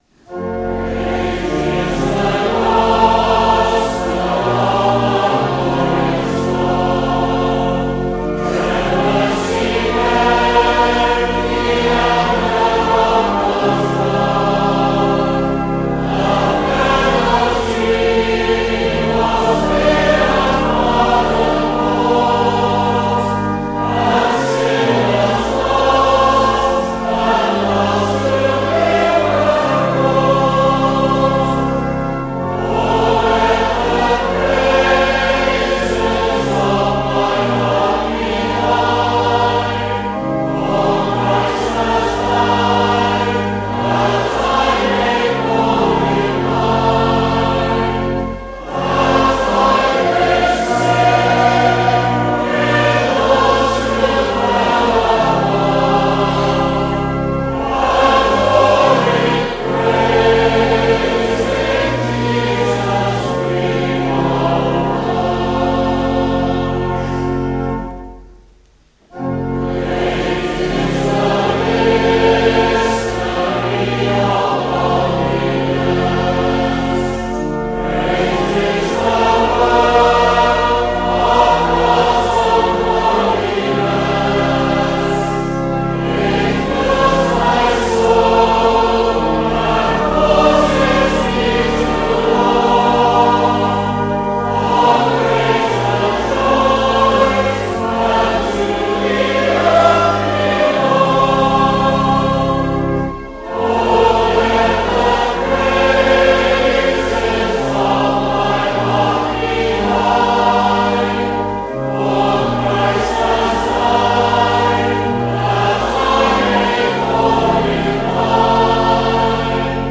» Hymn Singing » congregational singing of Vernon Higham's hymns
AudioTrack 03-Summer-Conference-Congregation-Great-is-the-Gospel.mp3